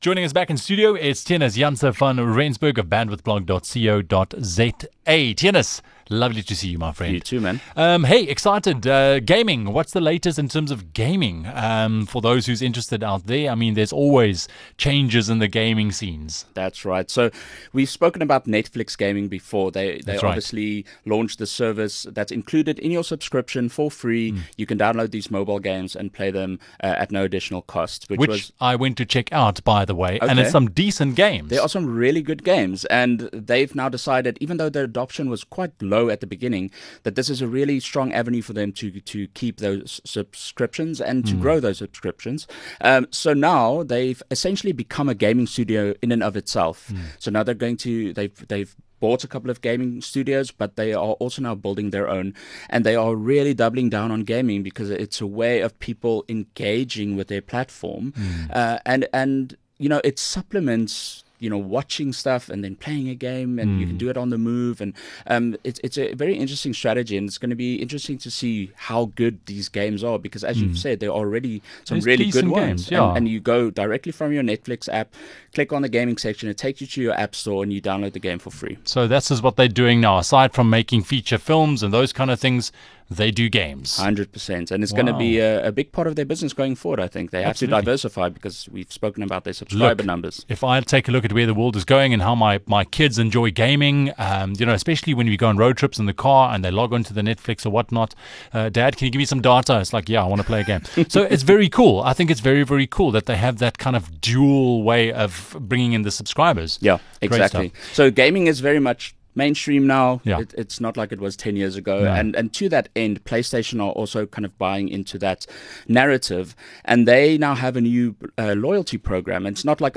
chat